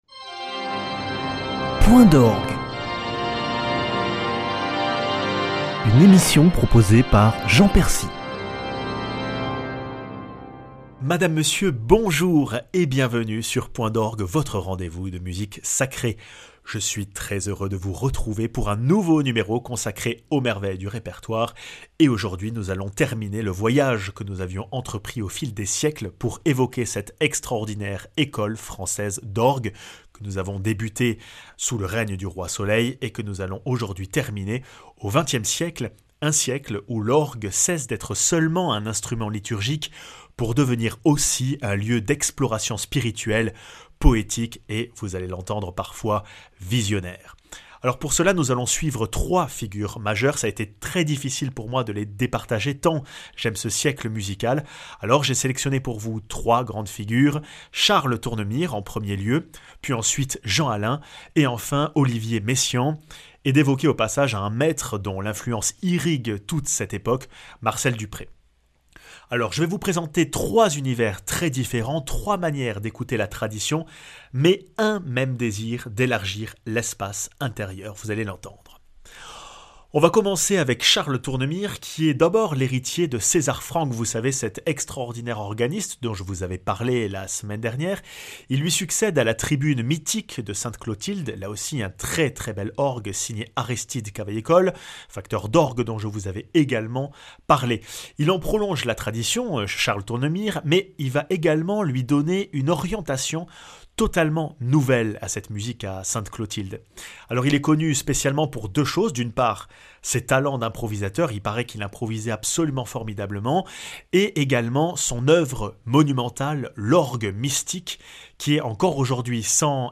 Point d'orgue termine son voyage dans l'orgue français du XXème en compagnie des plus grands maîtres. (Tournemire : Entrée du samedi saint, Alain : les Litanies et Messiaen : Les Anges)